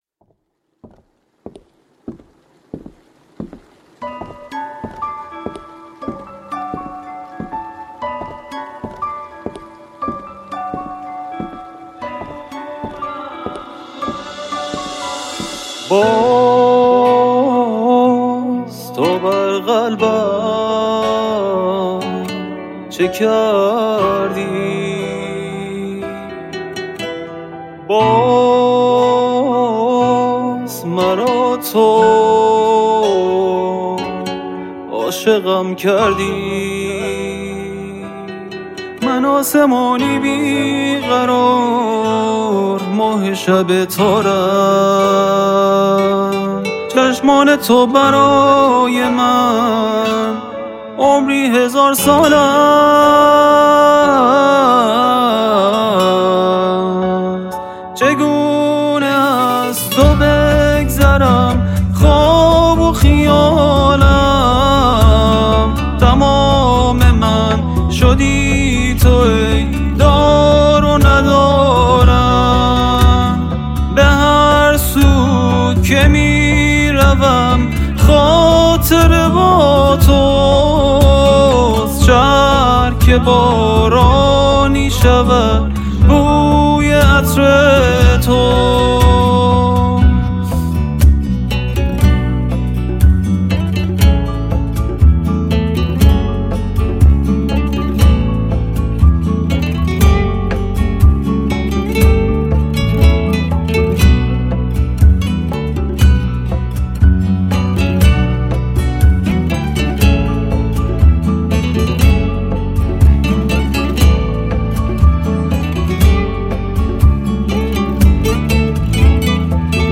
اهنگی ارامش بخش و پرمحتوا واحساسی خداییش به دل میشینه❤🌹👏